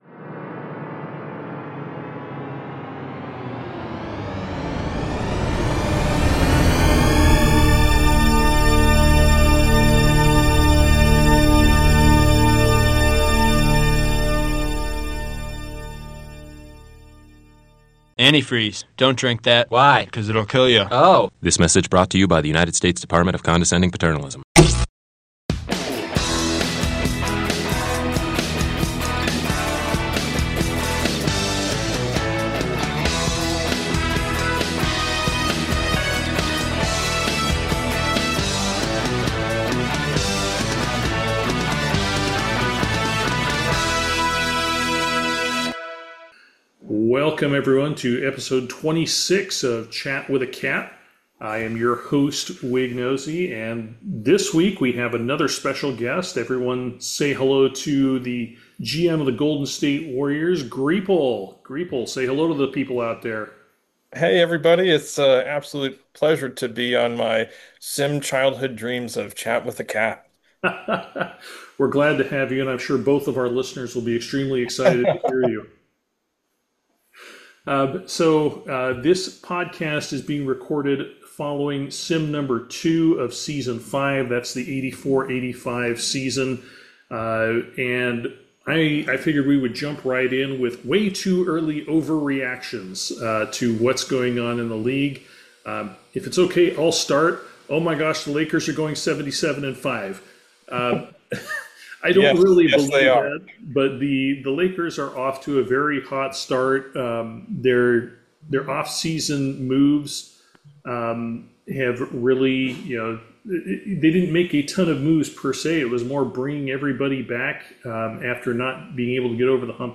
SPECIAL GUEST CO-HOST!